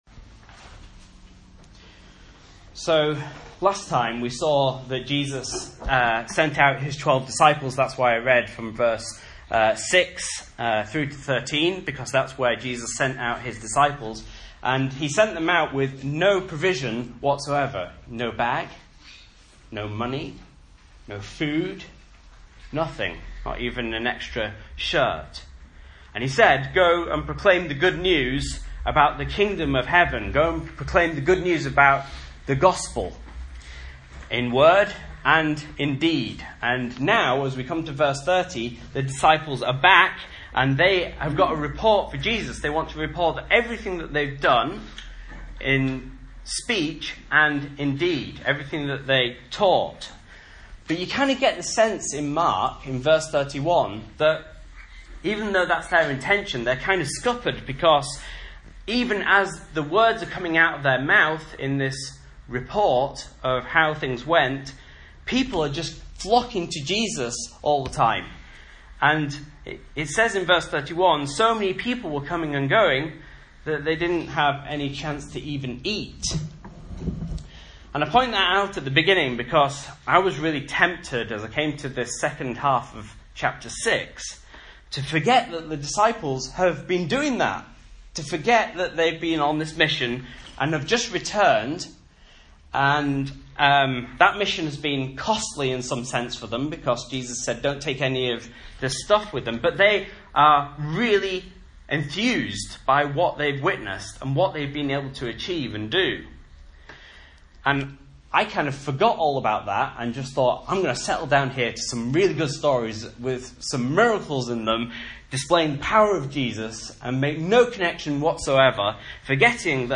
Message Scripture: Mark 6:30-56 | Listen